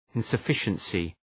Προφορά
{,ınsə’fıʃənsı}